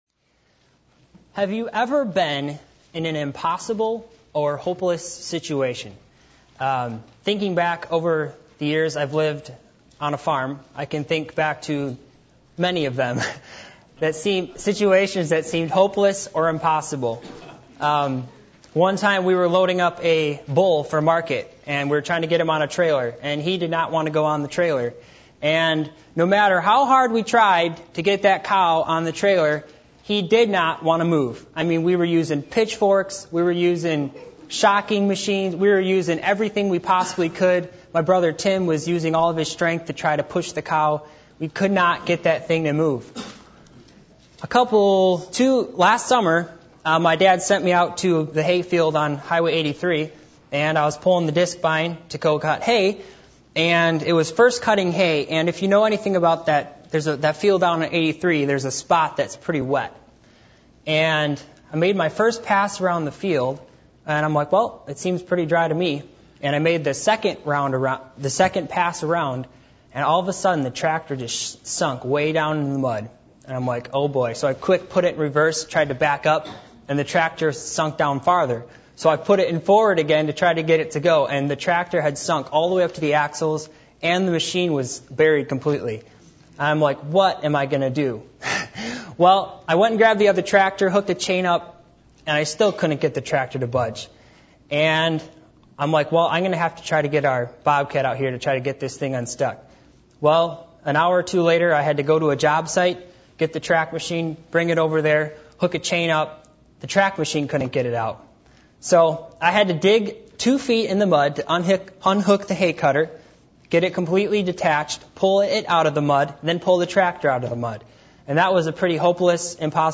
Passage: Mark 10:45-52 Service Type: Midweek Meeting %todo_render% « Our Journey Of Truth.